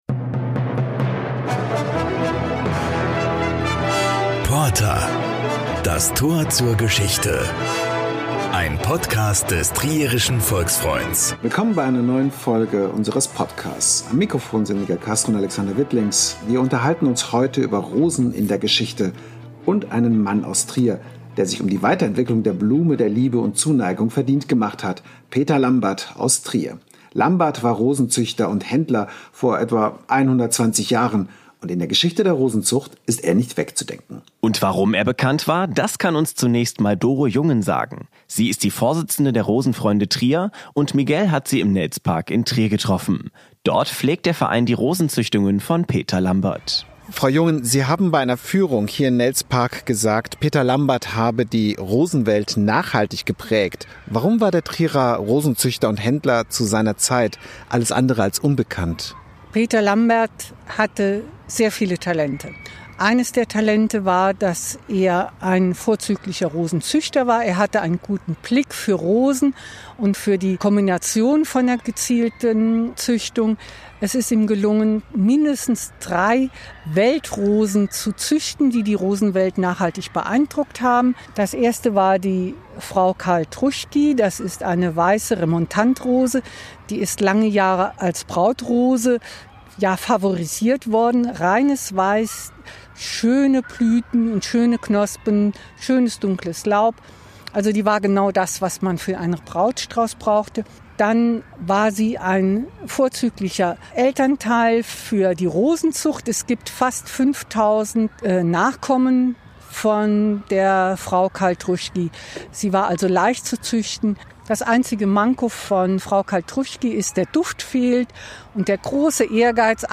Zwei Experten wissen mehr über den Mann zu berichten, an den heute in Trier eine Rosenausstellung in einem Park erinnert.